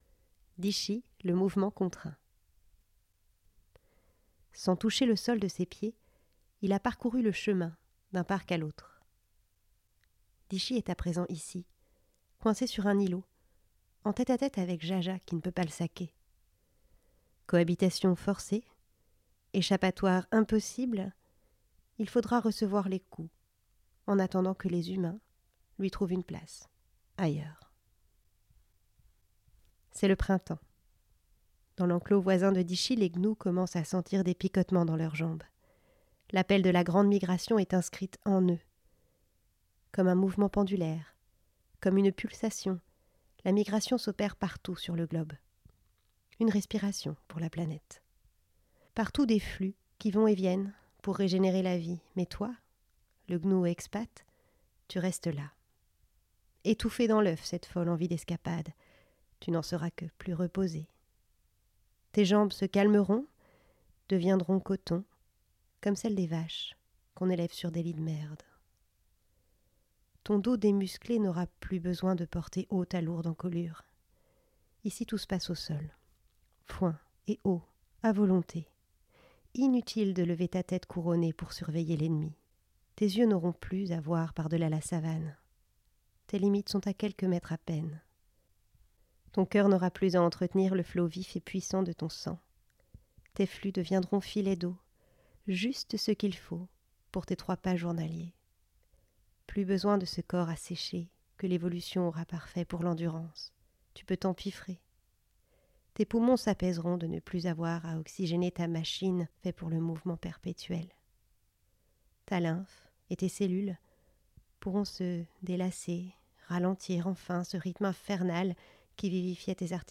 Écoutez un extrait sonore du livre, enregistré par la journaliste et podcasteuse